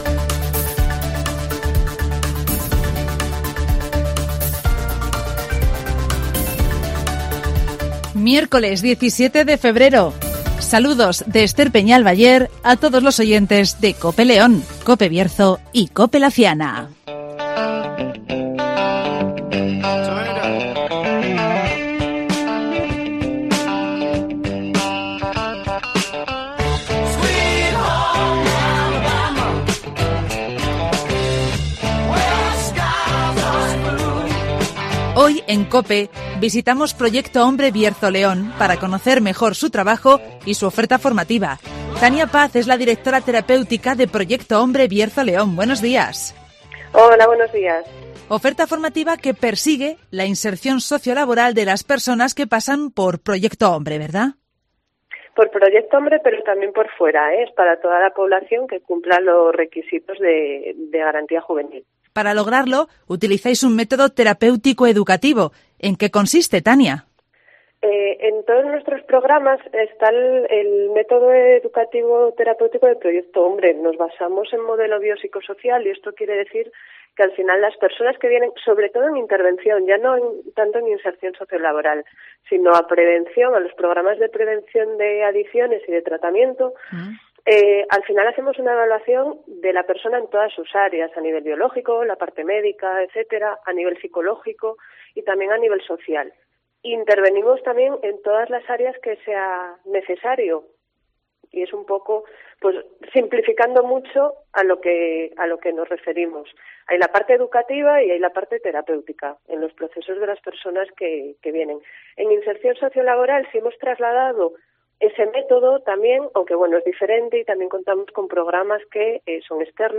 Proyecto Hombre Bierzo-León comienza el curso 'Técnicas y Preparación Física en Deportes de Contacto' (Entrevista